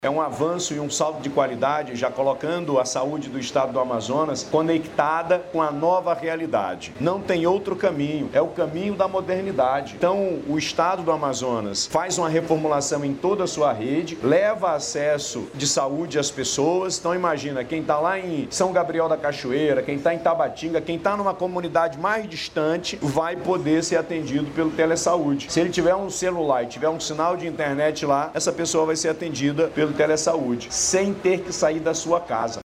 Durante o início dos atendimentos, o governador do Amazonas, Wilson Lima, disse que a nova modalidade vai beneficiar, principalmente, pessoas que moram em regiões longínquas.